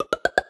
Special Pop (6).wav